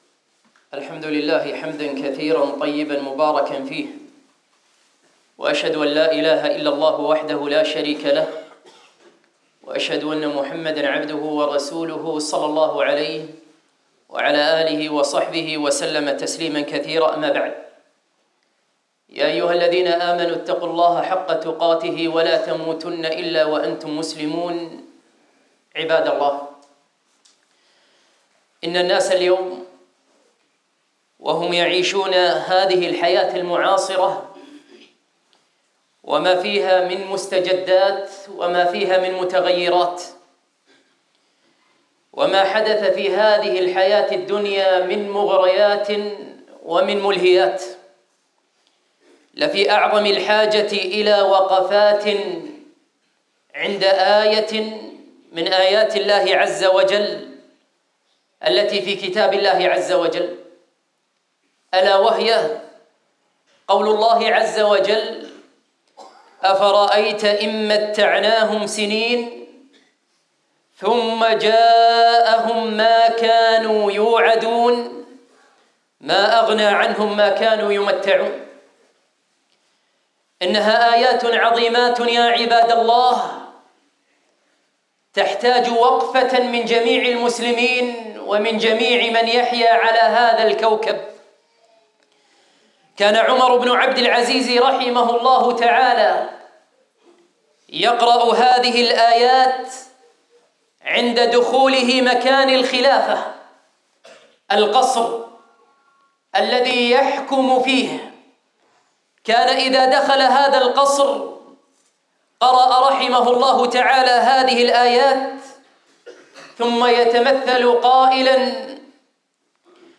خطبة - حقيقة الدنيا (ألقيت في فرنسا) - دروس الكويت